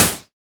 Index of /musicradar/retro-drum-machine-samples/Drums Hits/Tape Path B
RDM_TapeB_SY1-Snr02.wav